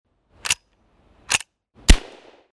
Android Ringtones
Ringtone-4-Lever-Action-Shot.mp3